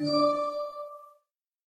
whisper.ogg